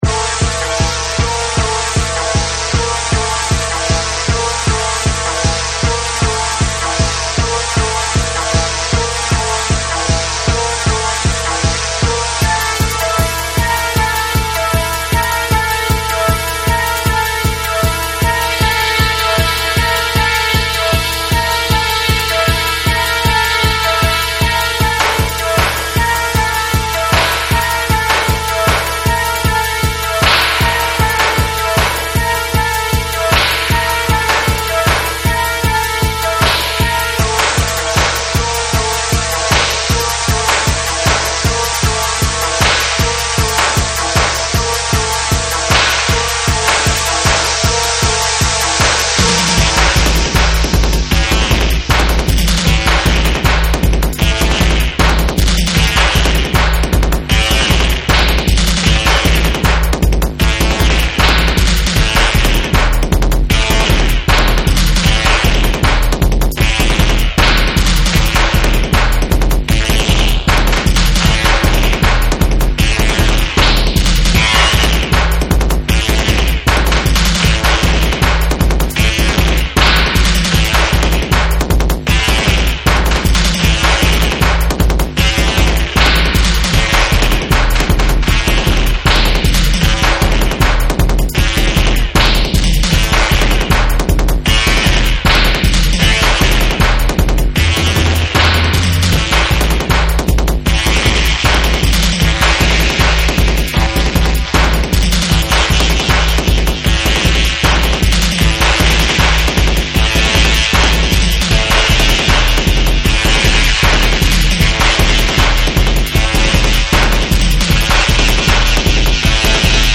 アグレッシヴな高速ステッパー
ゴリゴリのベースが渦を巻くエクスペリメンタル・ダブ
JAPANESE / REGGAE & DUB / NEW RELEASE(新譜)